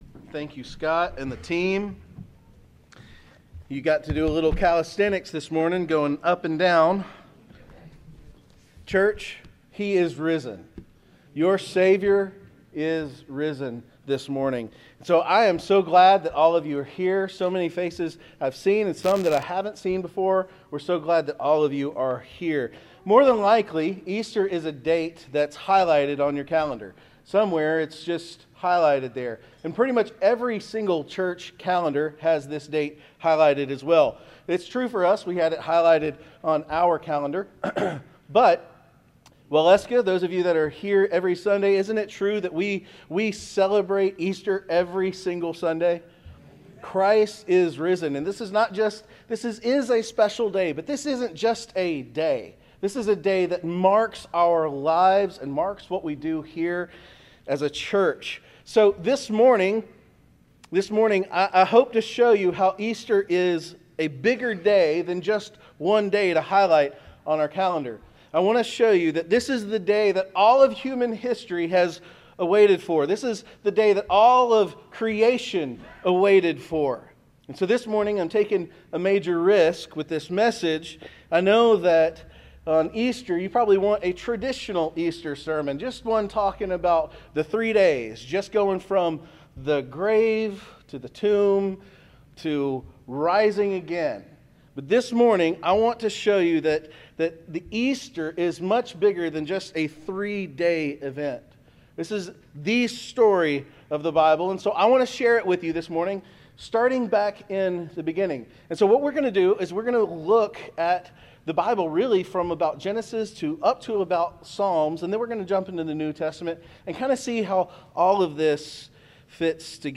Sermons | Waleska First Baptist Church